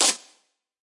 来自我的卧室的声音 "磁带延伸的小（冻结）。
描述：在Ableton中录制并略微修改的声音